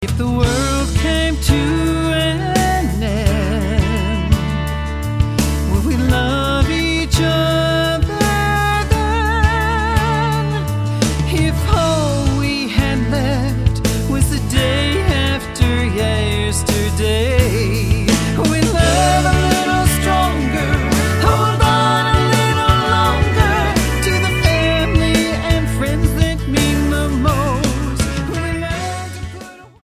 STYLE: Country
has a strong singing voice
the backing is pleasant modern country